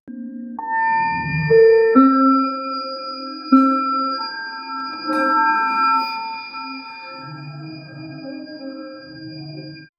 Quick UI sound for cancelling a restaurant reservation — two soft but distinct tones with different frequencies, played in quick succession. Sharp yet smooth, minimalistic, polite. Should feel final but not aggressive — ideal for a modern restaurant app. 0:10 Created Jun 3, 2025 6:48 AM
quick-ui-sound-for-cancel-nazy6zgj.wav